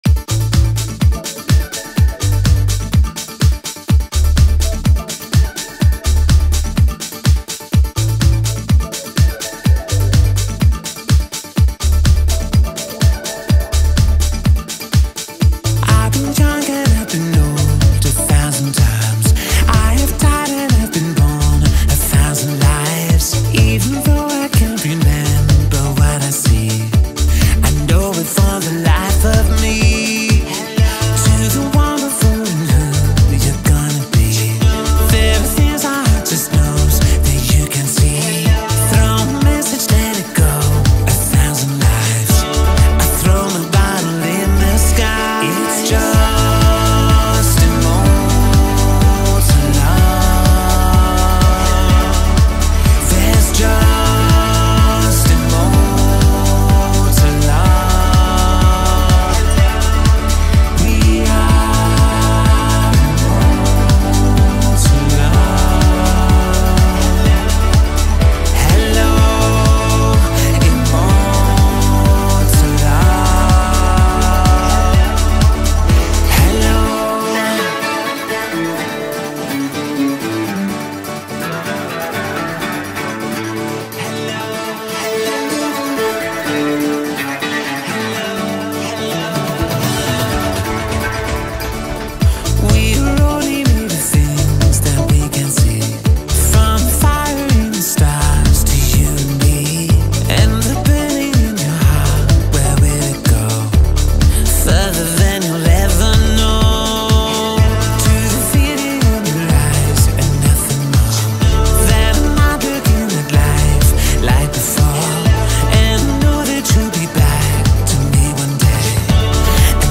theatrical emotion